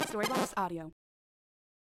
retro-sound.mp3